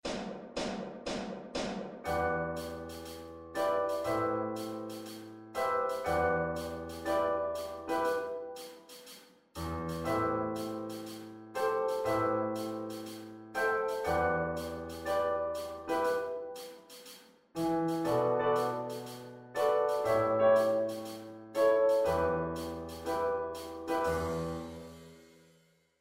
Em pentatonic scale improvising accompaniment (faster track)